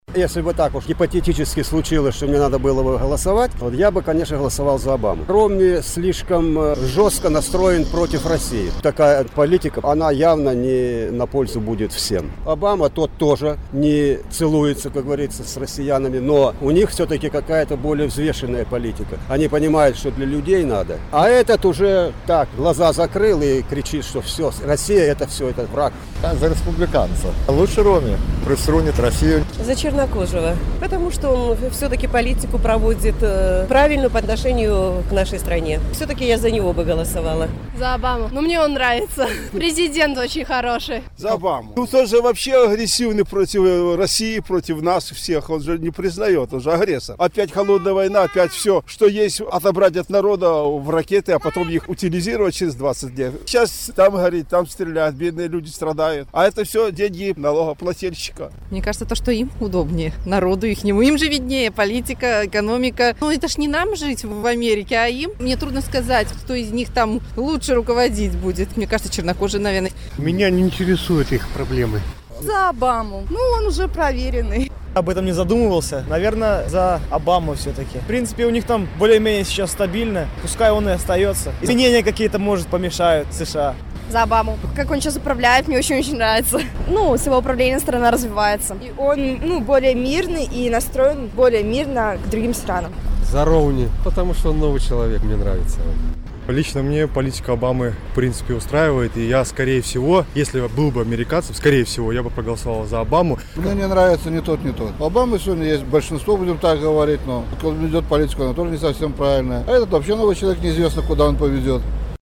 За каго вы прагаласавалі б на амэрыканскіх выбарах 6 лістапада? Адказваюць жыхары Гомелю